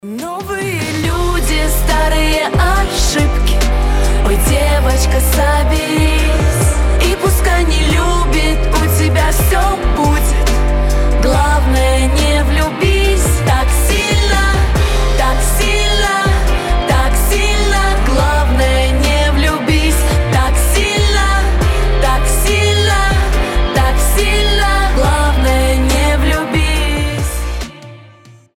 • Качество: 320, Stereo
женский голос
дуэт
медленные